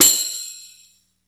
percussion 08.wav